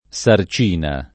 vai all'elenco alfabetico delle voci ingrandisci il carattere 100% rimpicciolisci il carattere stampa invia tramite posta elettronica codividi su Facebook sarcina [ S ar ©& na ] o sarchina [ S ark & na ] s. f. (chim.